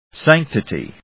音節sanc・ti・ty 発音記号・読み方
/sˈæŋ(k)təṭi(米国英語), ˈsæŋktɪti:(英国英語)/